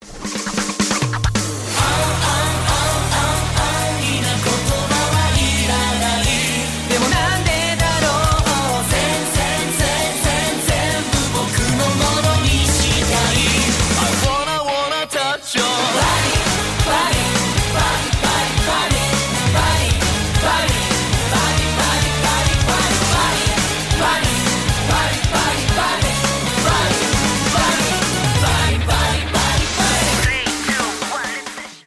j-rock